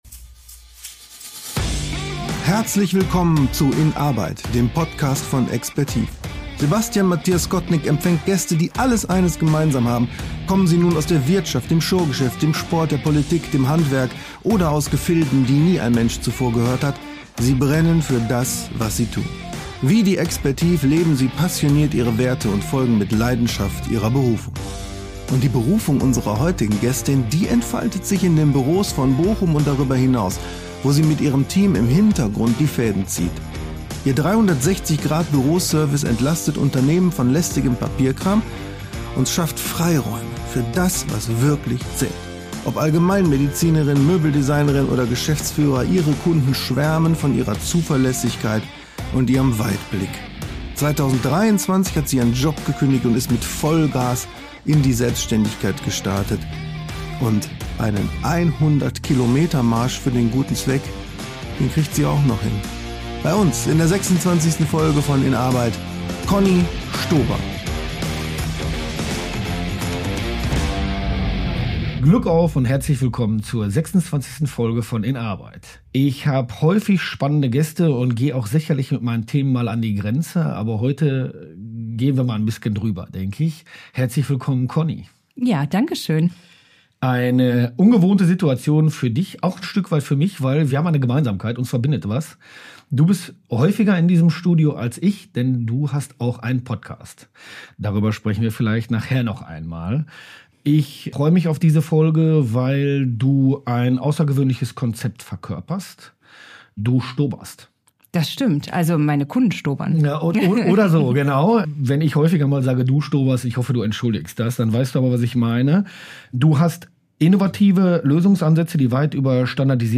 Ein Gespräch über modernste Buchhaltung am Puls der Zeit, tiefes Vertrauen und die Freude an steten Herausforderungen.